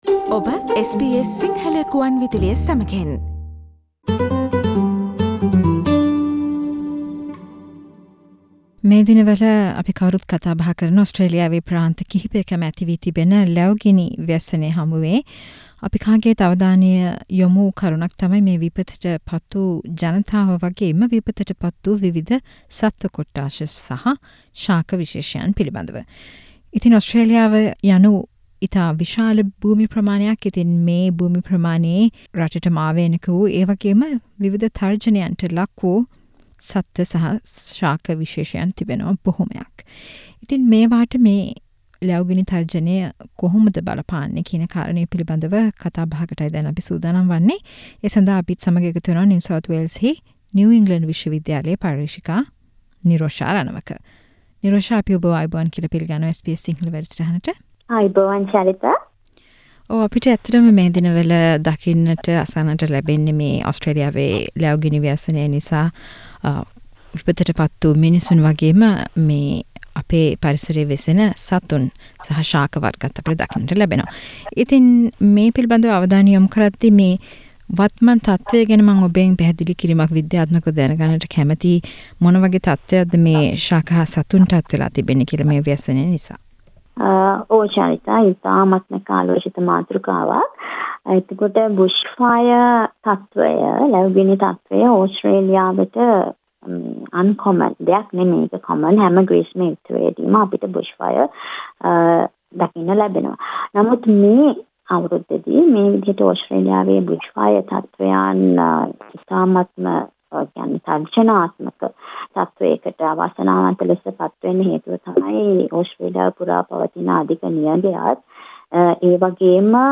සමගින් SBS සිංහල සේවය කල පිළිසදර